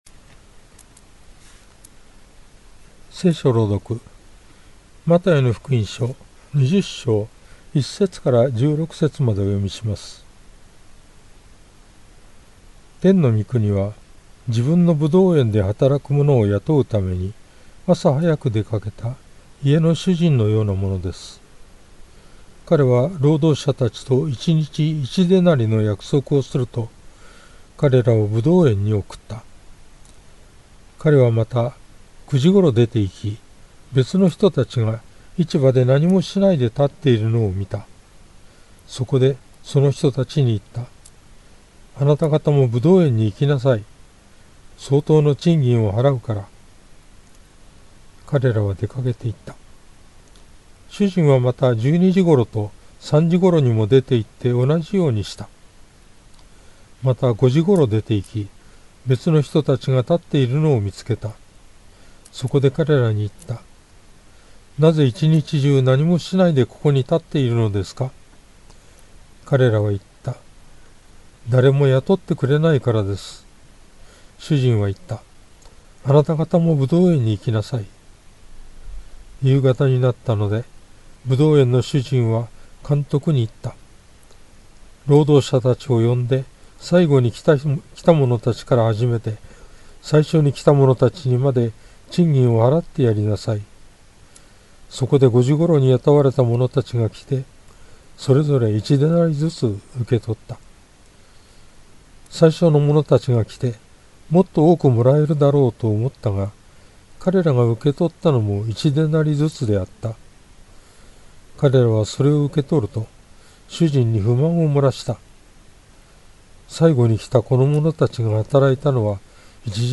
BibleReading_Math20.1-16.mp3